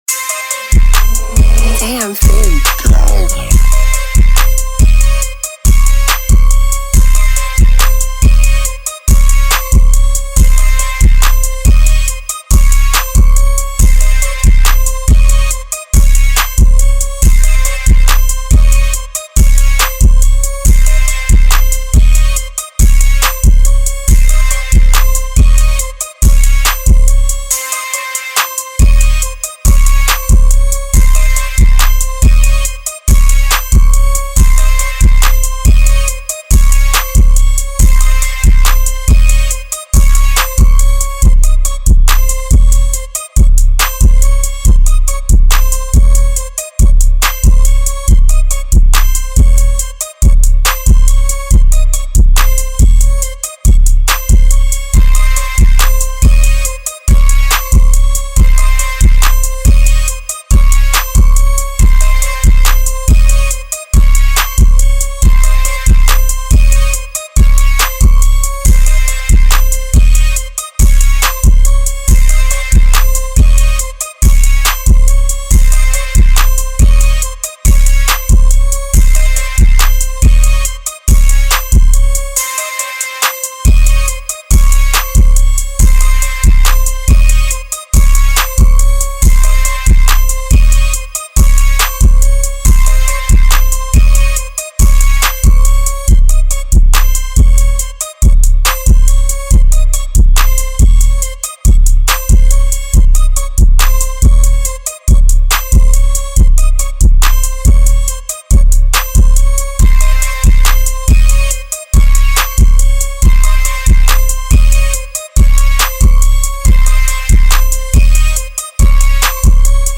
Trap Instrumental